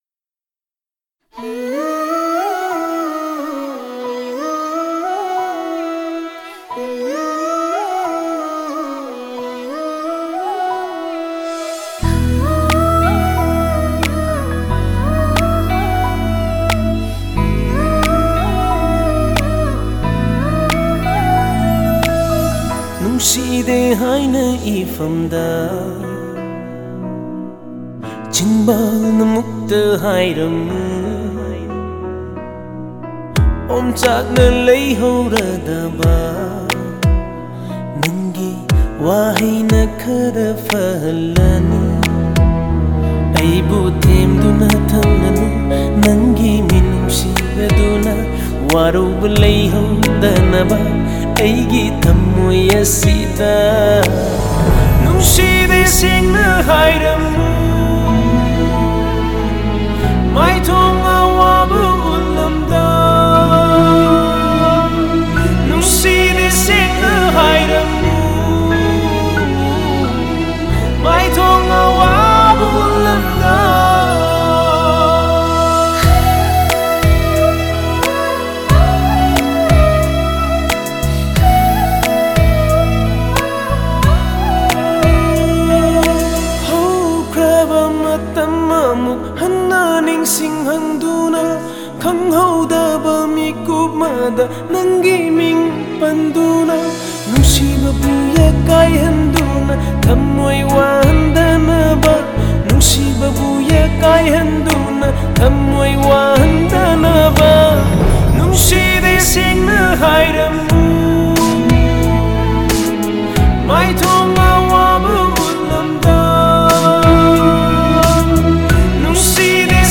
A Manipuri Feature Film